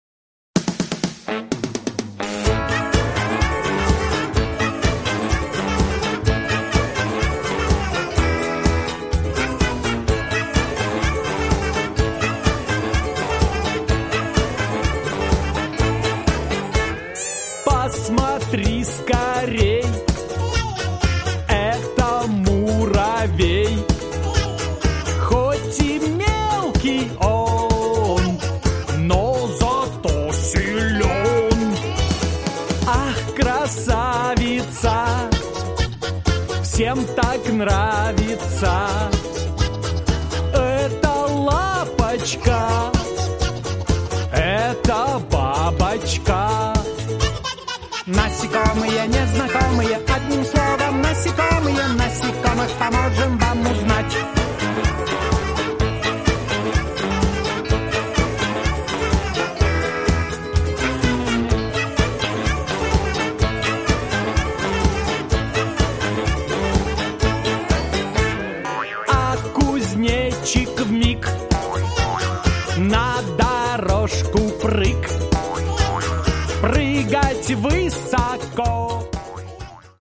Шелест крыльев звук